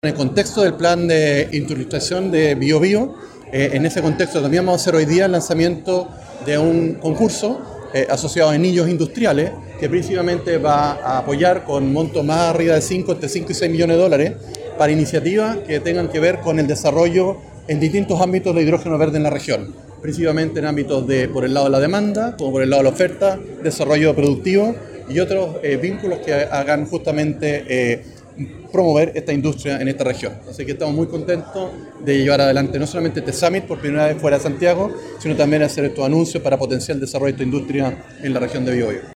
Este martes se realizó en Biobío el Green Hydrogen Summit Chile LAC 2025, instancia donde se abordaron las oportunidades y desafíos del desarrollo energético regional, por primera vez fuera de Santiago, considerando el rol estratégico de la industria local.